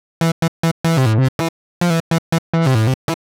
Index of /musicradar/uk-garage-samples/142bpm Lines n Loops/Synths